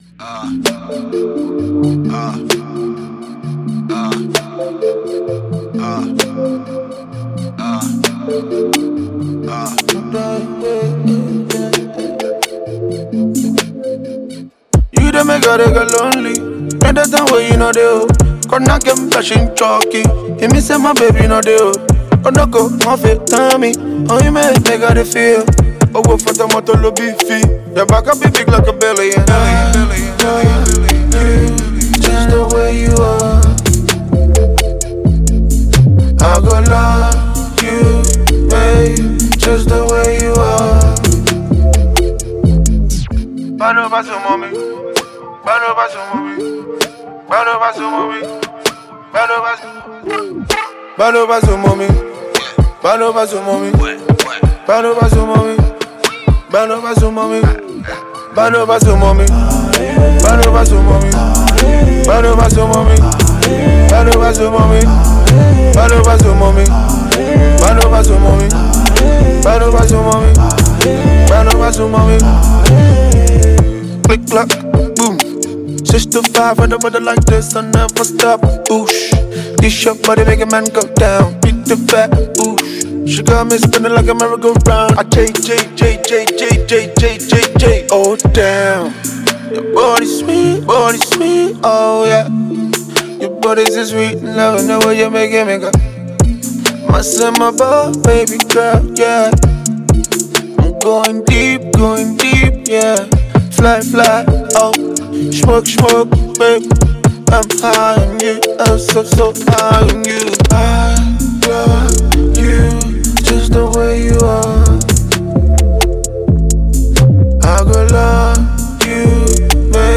This energetic track blends infectious Afrobeat rhythms
vibrant percussion and melodic synths